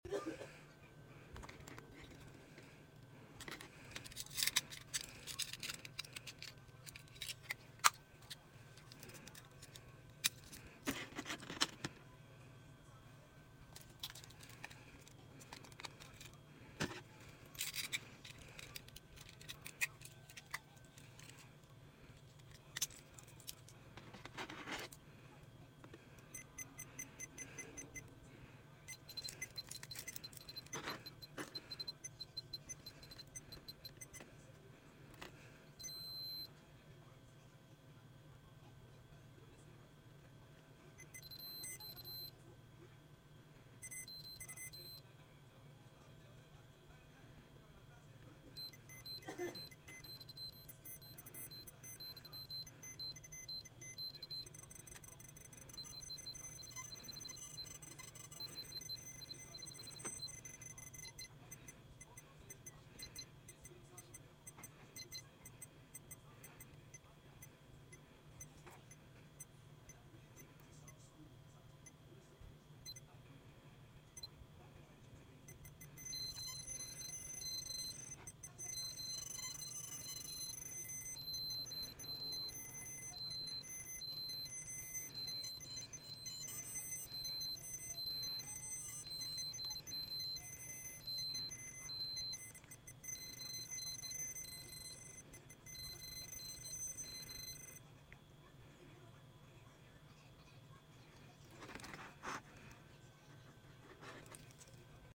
Here is the mating tamagotchi, sound effects free download
Mp3 Sound Effect Here is the mating tamagotchi, osutchi and mesutchi, finally breeding!